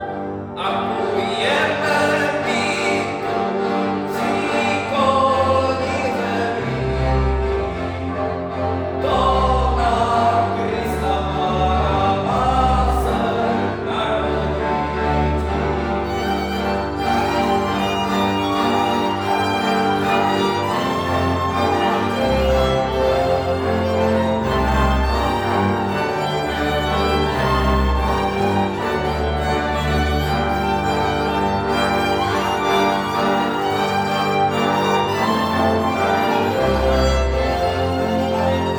Adventní koncert studentů Pedagogické fakulty MU přinesl vánoční náladu
Brno - Brňané si dnes v sedm večer v Sále Milosrdných bratří mohli poslechnout skladby Jiřího Ignáce Lineka nebo lidové vánoční písně.
Zatímco Pěvecký sbor Pedagogické fakulty MU a orchestr Collegium musicum se věnují převážně interpretaci klasické hudby, cimbálový soubor Municimbal propojuje tradiční moravskou cimbálku s moderním přístupem.
Lidové vánoční písně v podání souboru Municimbal zněly například takto: